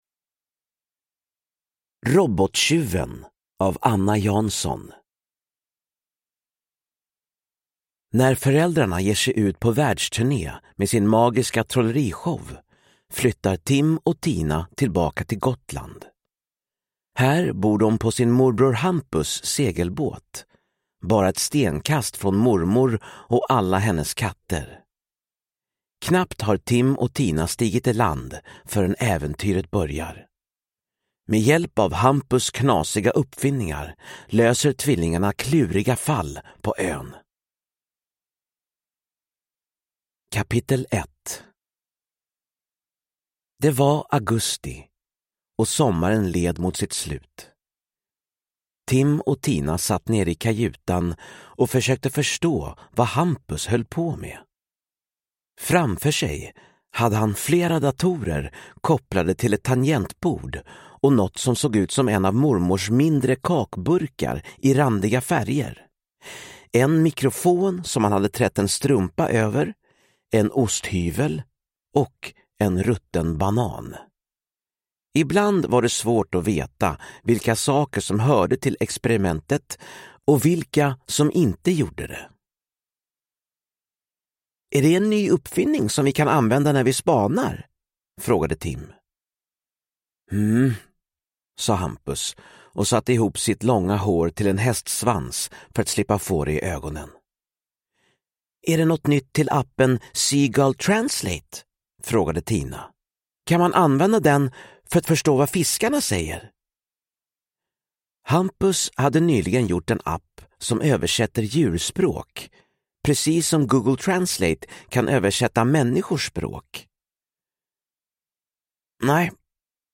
Robottjuven – Ljudbok